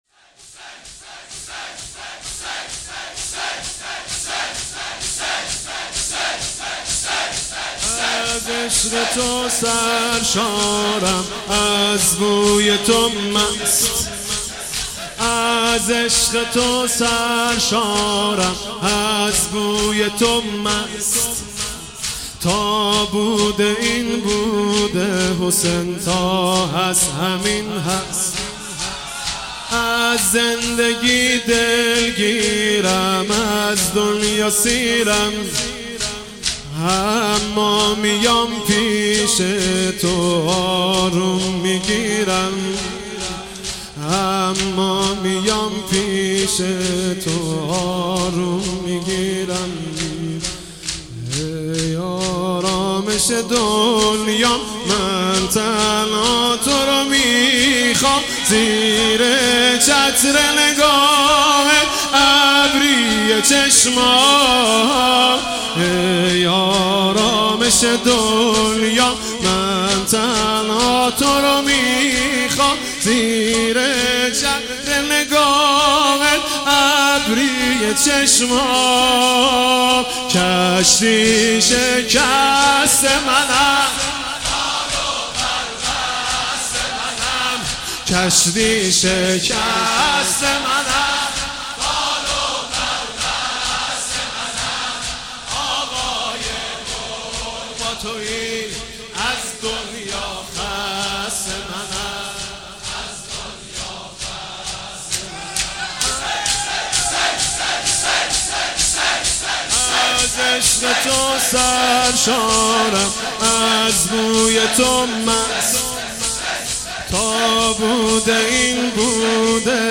شب سوم محرم97